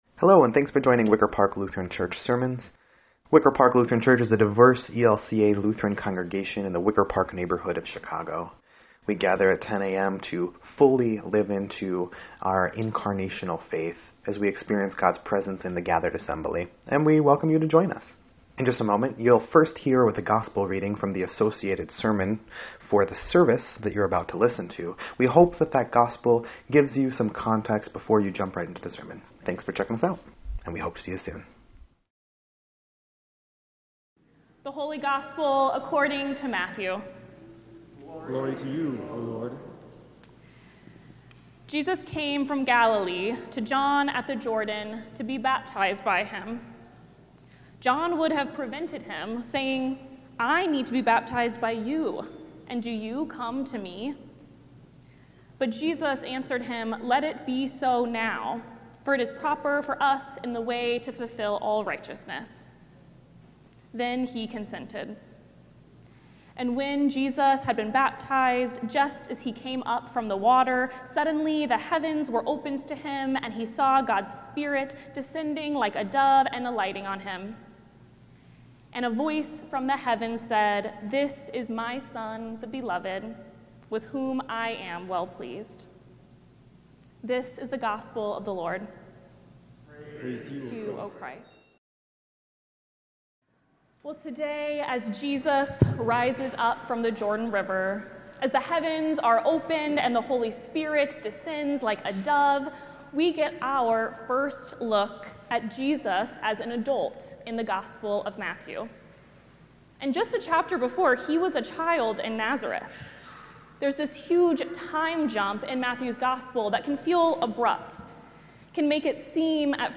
1.18.26-Sermon_EDIT.mp3